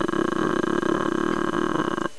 They rumble
RRRRRR.....RRRRRRR!
gator.wav